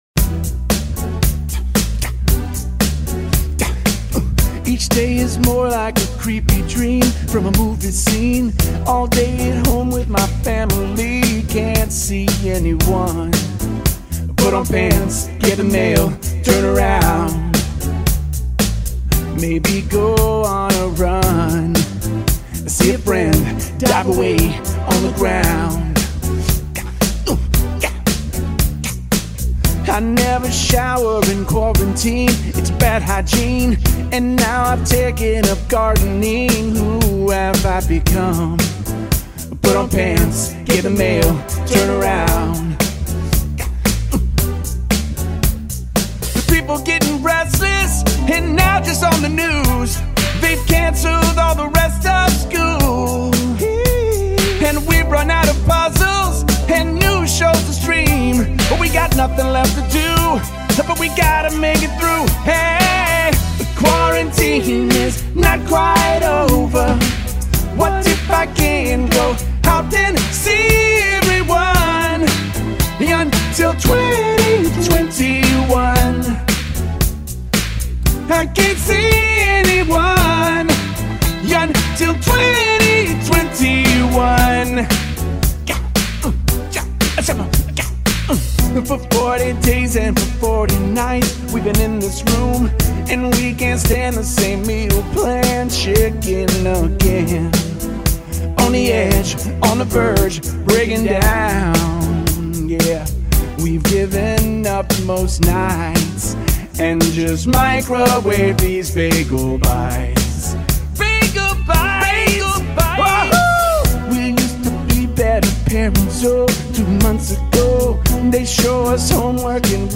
17) CLOSING PARODY SONG